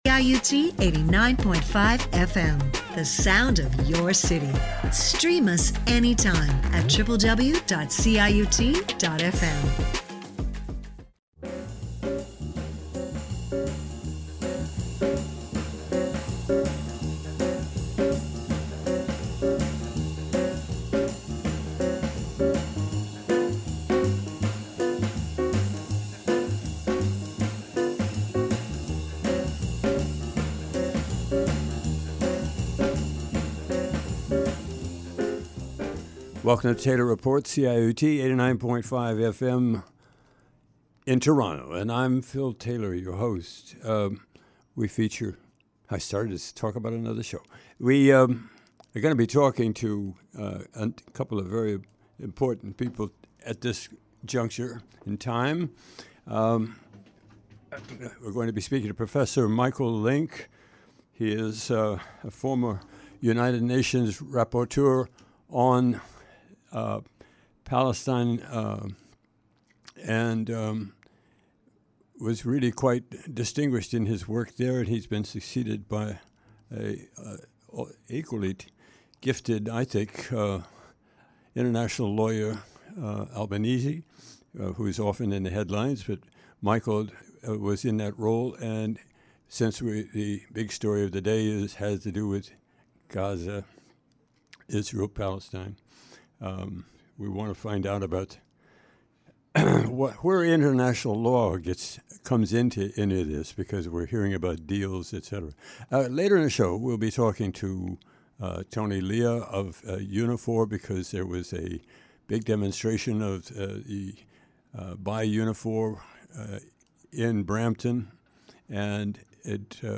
Interview with Prof. Michael Lynk